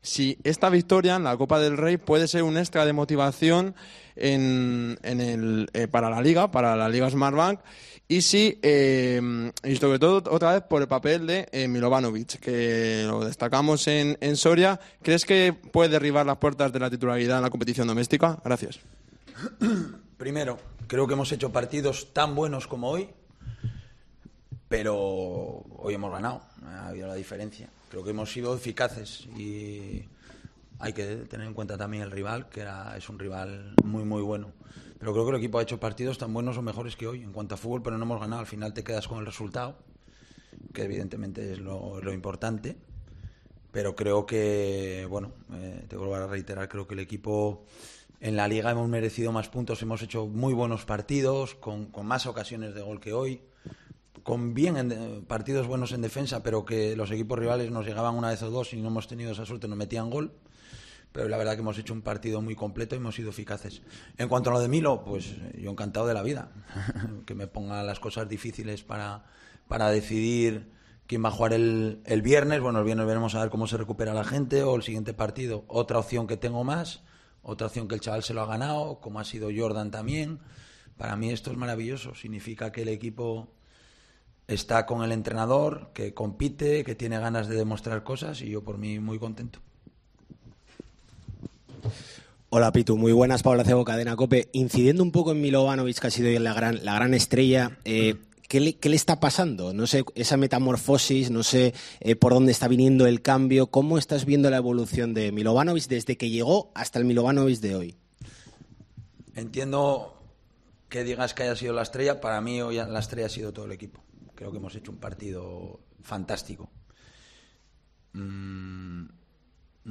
Rueda de prensa Abelardo (post Rayo Vallecano)